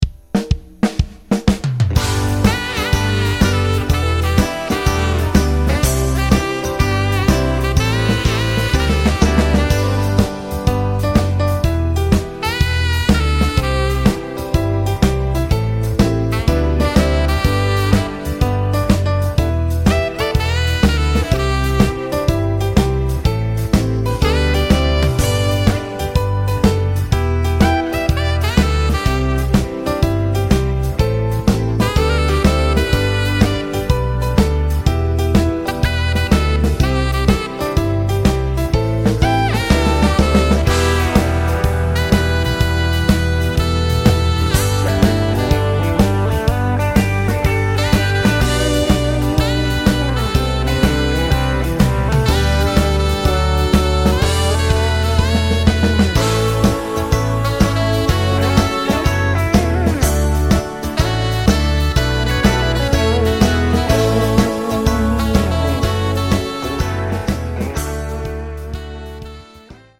Square Dance Music